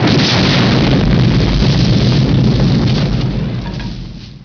爆炸的声音 爆炸音效
【简介】： 爆炸的声音、爆炸坍塌音效